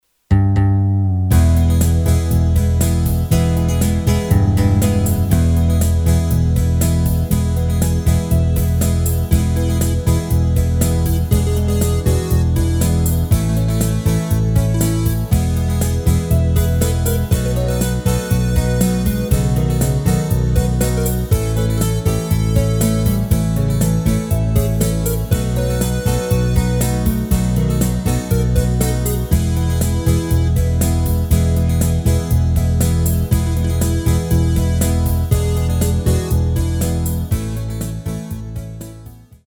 Rubrika: Folk, Country
tempo di beguine